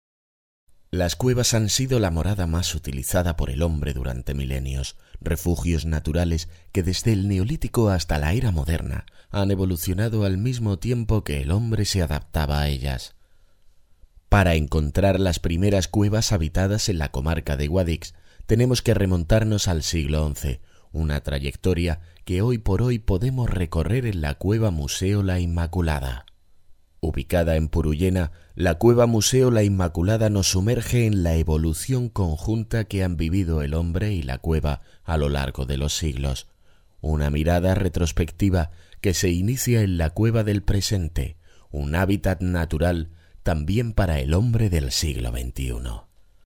Medium age male, warm, dynamic, confident and deep or young male, funny and very clear
kastilisch
Sprechprobe: Werbung (Muttersprache):